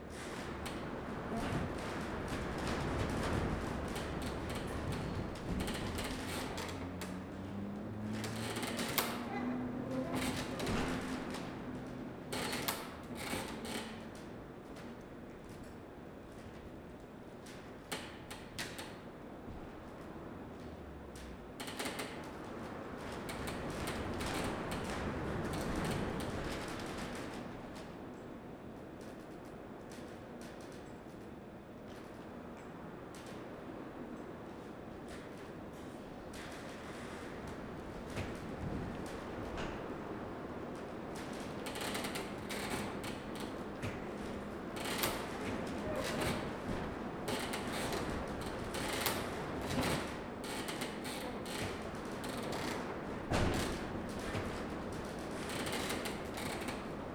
Add ambiance and packing buzzers
warehouse_quiet.wav